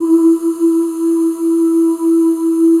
E3 FEM OOS.wav